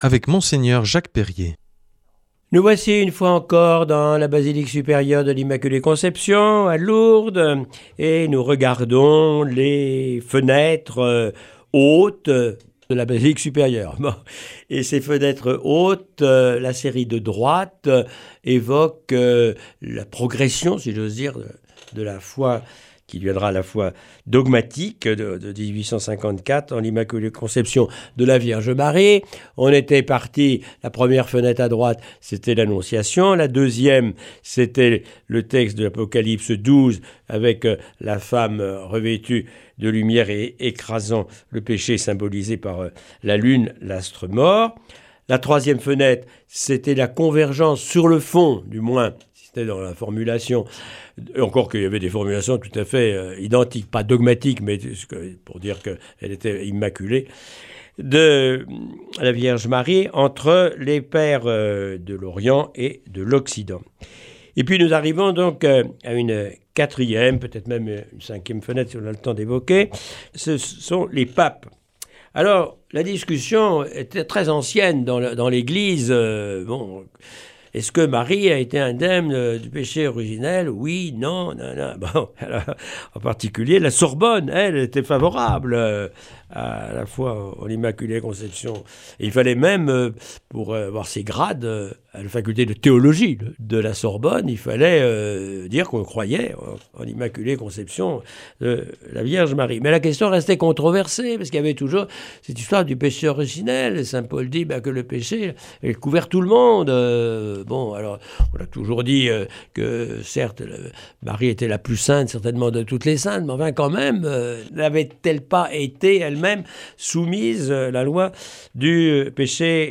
Cette semaine, Mgr Jacques Perrier nous propose de poursuivre la découverte des vitraux de la bisilique de l’Immaculée Conception à Lourdes. Aujourd’hui l’Immaculées Conception et les papes.